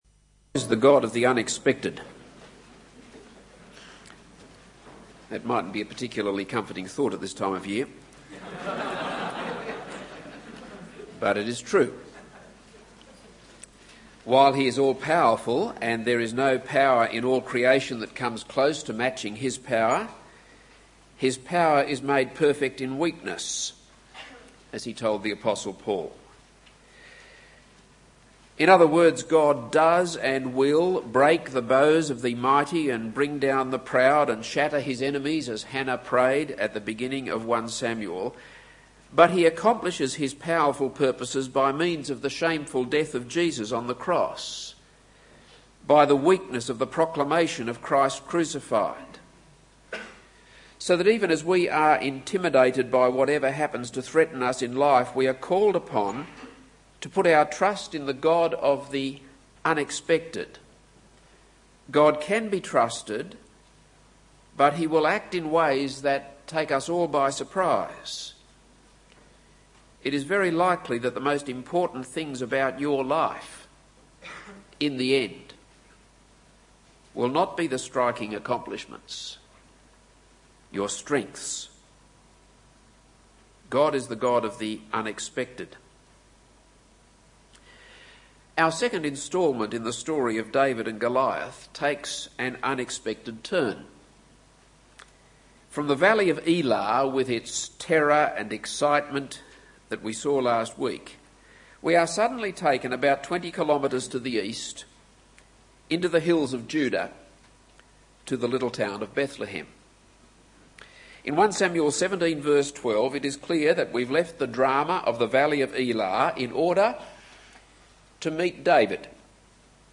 This is a sermon on 1 Samuel 17.